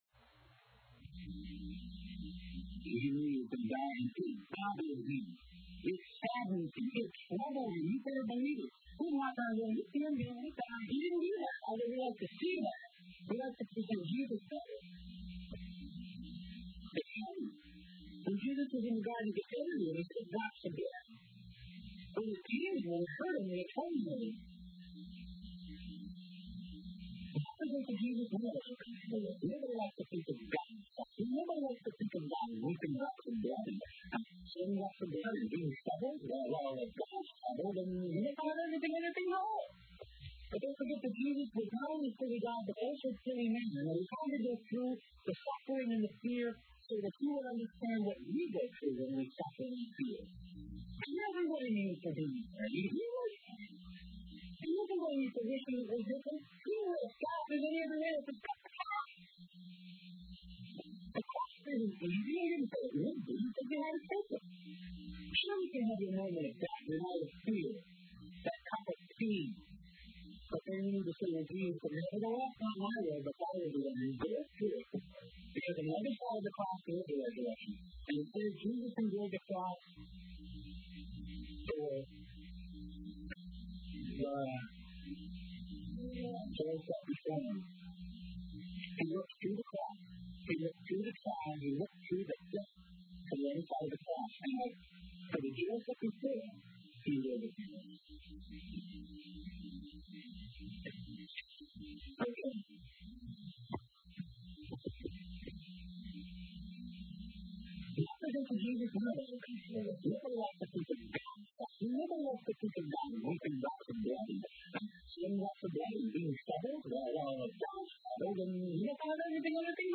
In this sermon, the speaker emphasizes the importance of understanding the human experience and the suffering and fear that people go through.